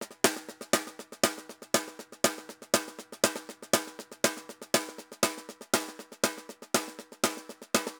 Tambor_Baion 120_2.wav